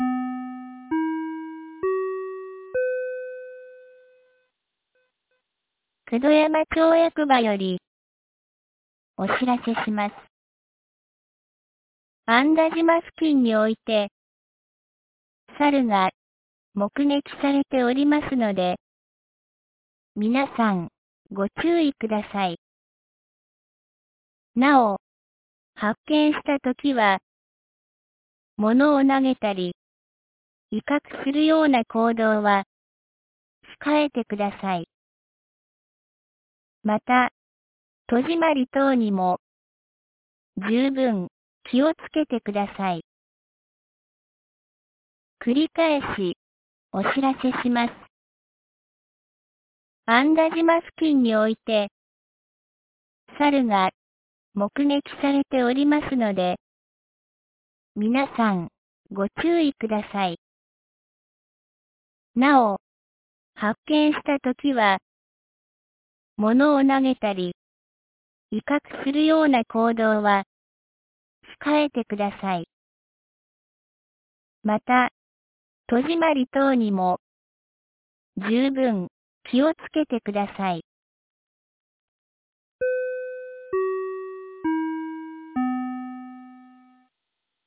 2026年04月04日 12時11分に、九度山町より九度山地区、入郷地区、慈尊院地区へ放送がありました。